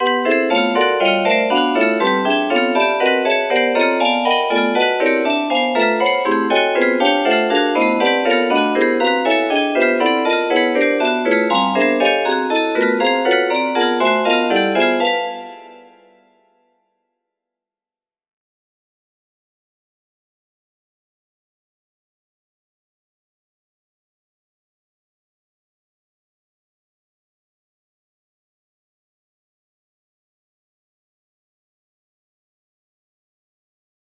P11 music box melody